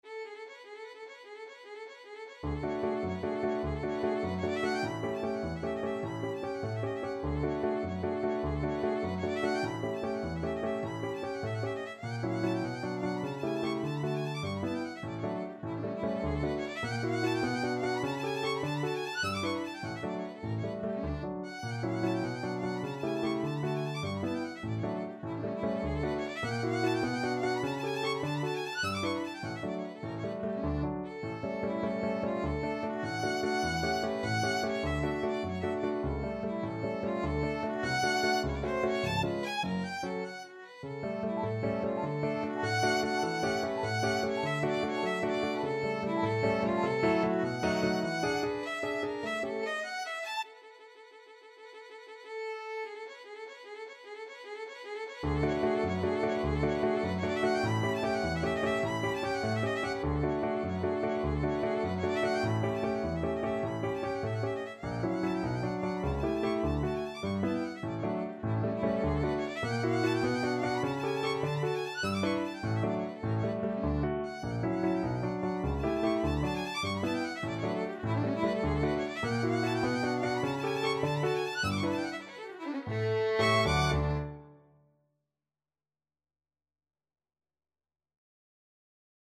Violin version
ViolinPiano
3/4 (View more 3/4 Music)
A4-F#7
Molto vivace .=100
Classical (View more Classical Violin Music)